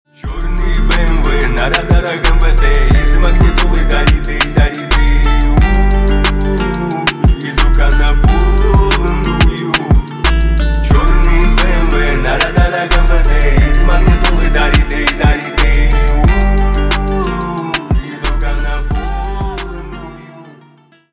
на русском гангстерские крутые